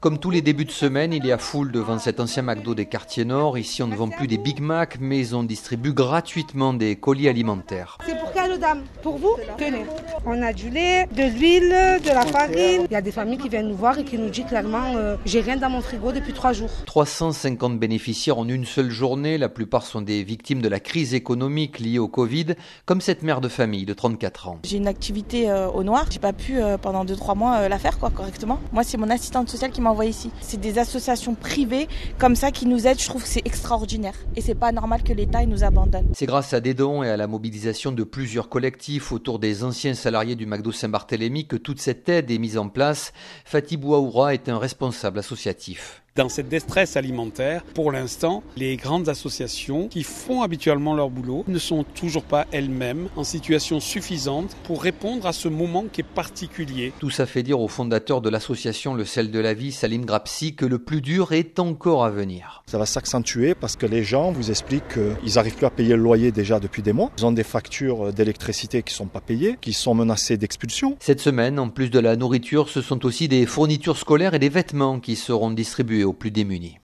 Un ancien Mac Donald des quartiers nord de Marseille a donc été transormé en plateforme alimentaire où l'on y distribue gratuitement de la nourriture. Rencontre avec les responsables et les bénéficiaires de ce système.